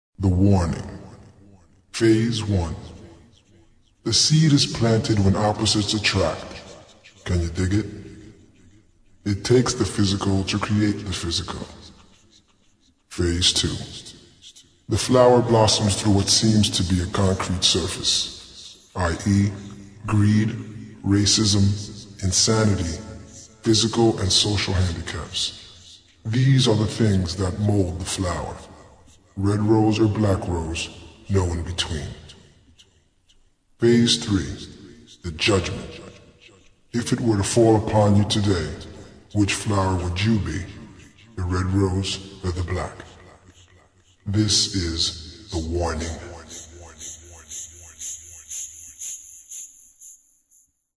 [ HOUSE / DEEP HOUSE ]